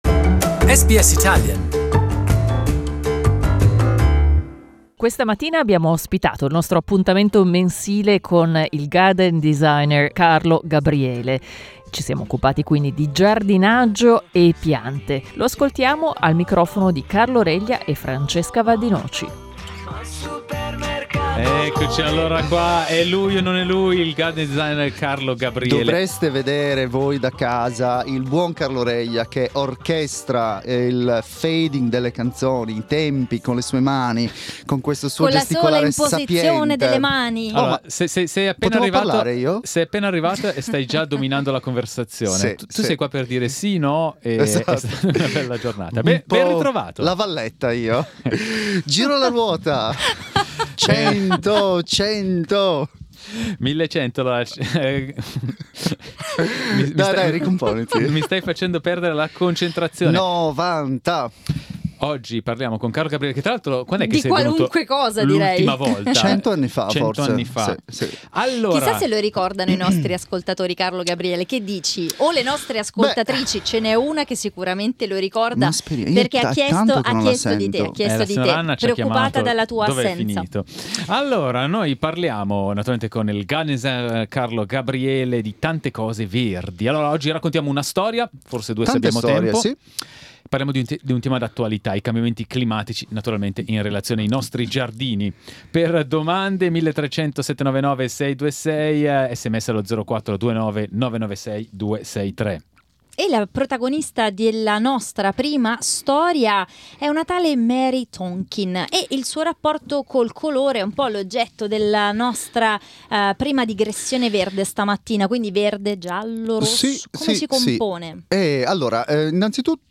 Our monthly chat with garden designer